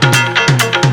7 Pastel Guitar Long 2.wav